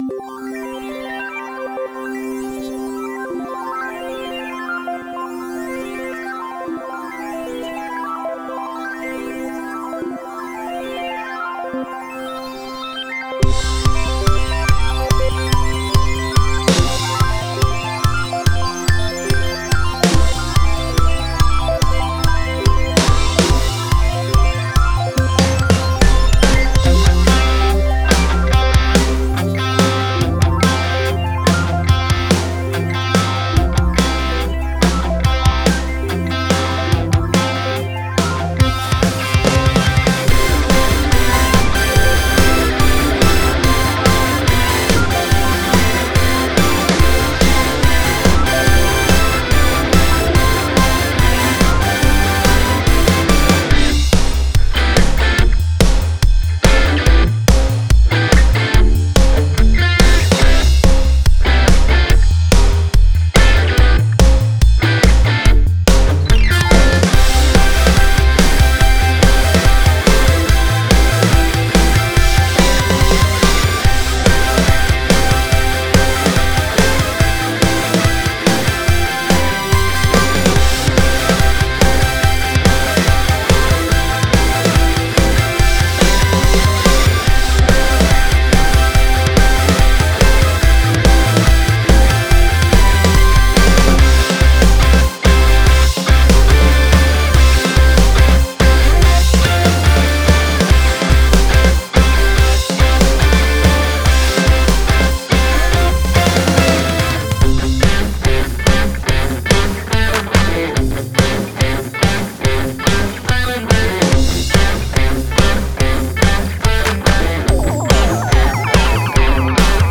今回の楽曲はアイドルっぽい曲というのをテーマに作られた作品。
▼Off vocalあります